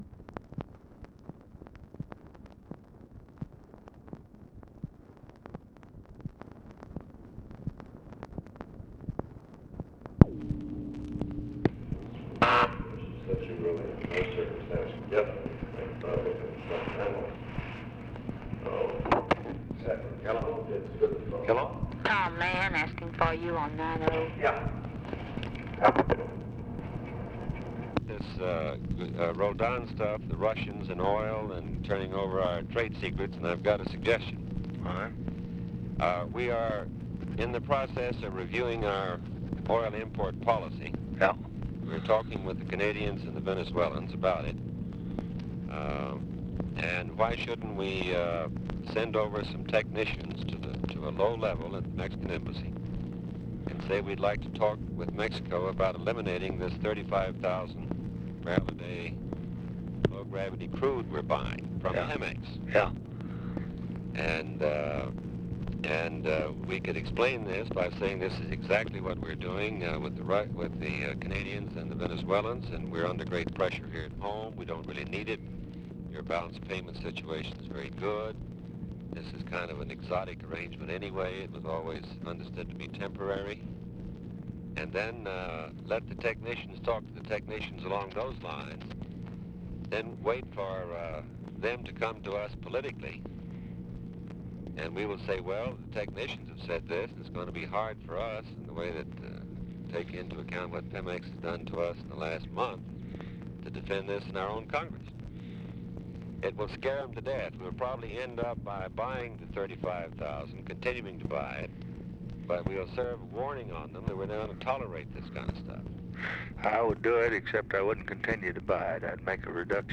Conversation with THOMAS MANN and OFFICE CONVERSATION, November 18, 1964
Secret White House Tapes